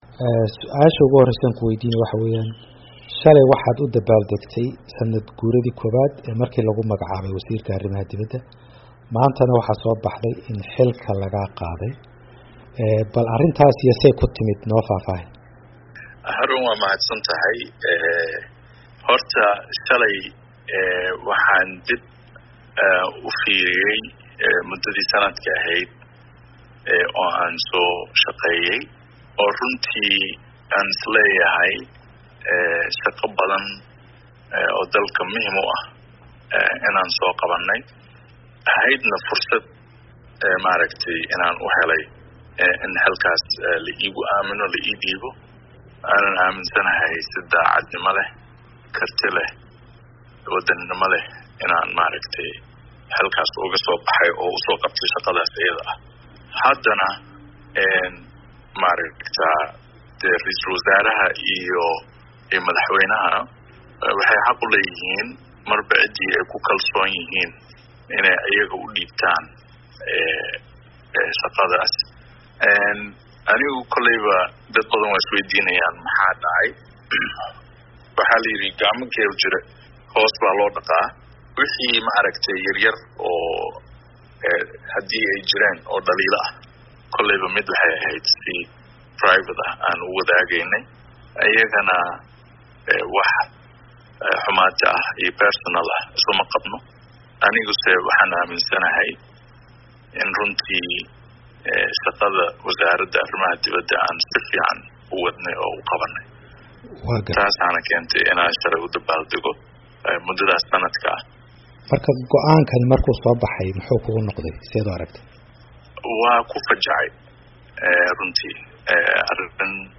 Halkan ka dhageyso wareysiga wasiir Maxamed Cabdirisaaq